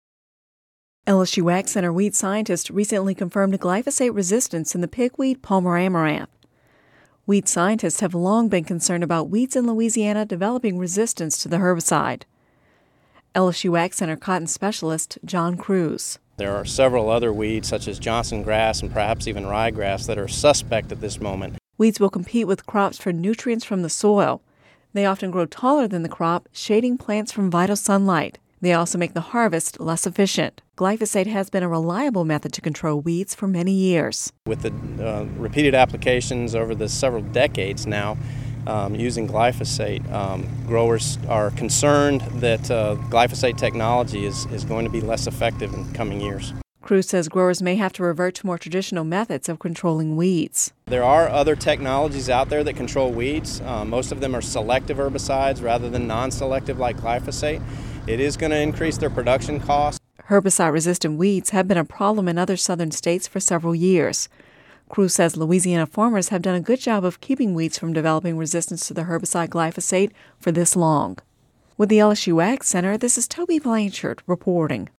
(Radio News 09/27/10) LSU AgCenter weed scientists recently confirmed glyphosate resistance in the pigweed, palmer amaranth. Weed scientists have long been concerned about weeds in Louisiana developing resistance to the herbicide.